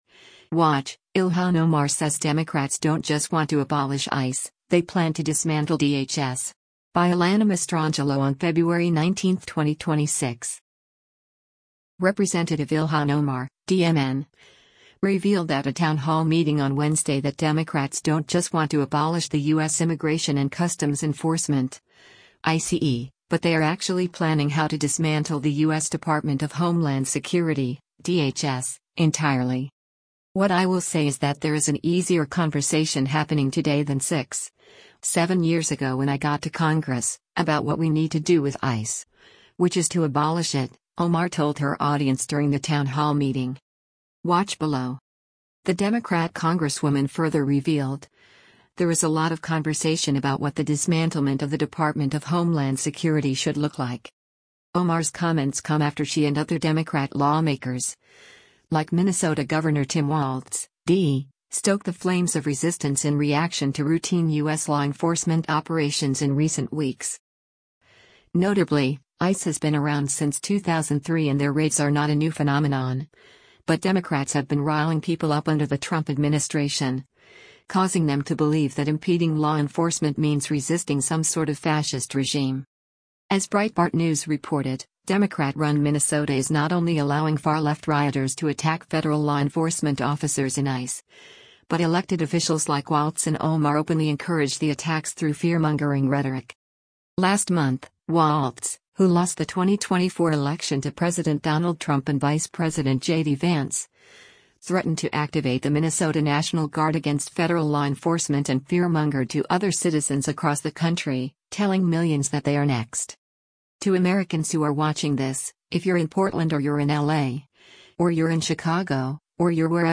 Rep. Ilhan Omar (D-MN) revealed at a townhall meeting on Wednesday that Democrats don’t just want to abolish the U.S. Immigration and Customs Enforcement (ICE), but they are actually planning how to dismantle the U.S. Department of Homeland Security (DHS) entirely.